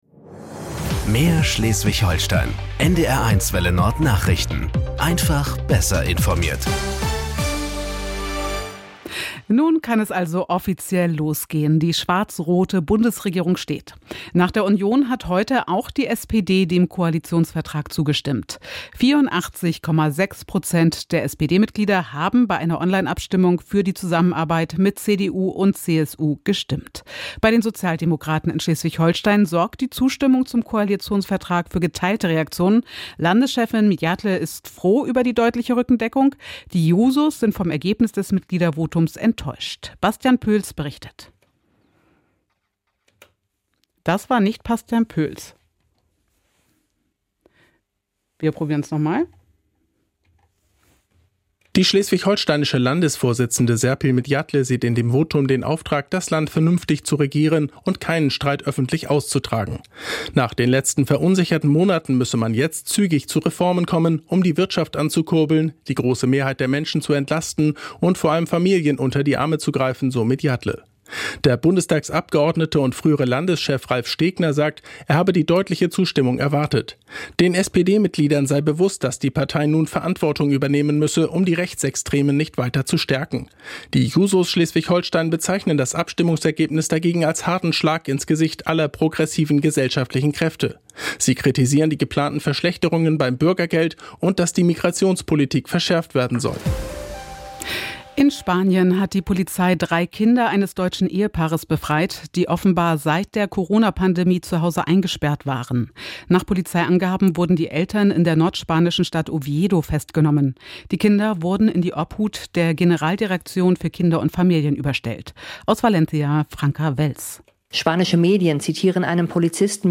… continue reading 2 episoder # Tägliche Nachrichten # Nachrichten # NDR 1 Welle Nord